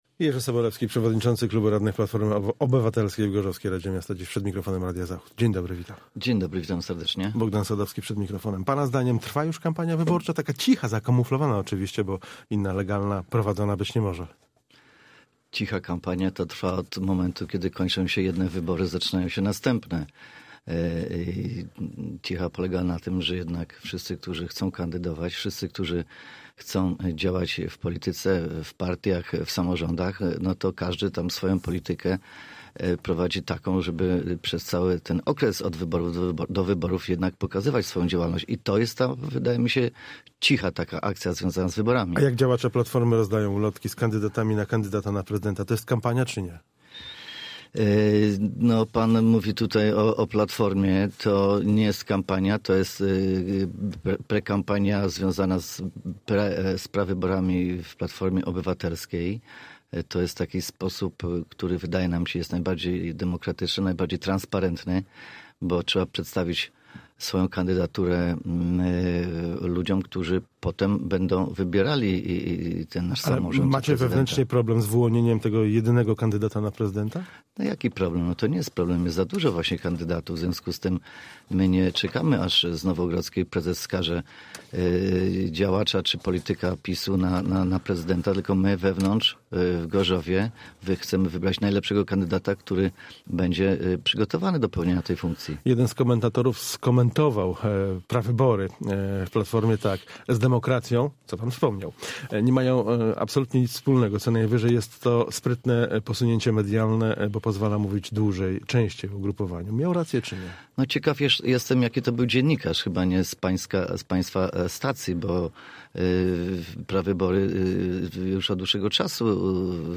Z przewodniczącym klubu radnych PO w gorzowskiej Radzie Miasta